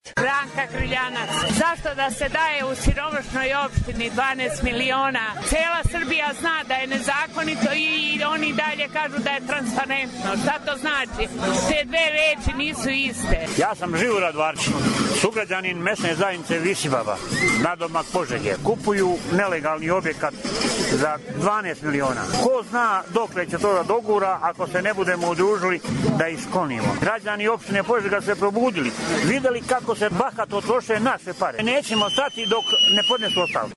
Neki od građana Požege prisutnih na protestu ovo su nam rekli: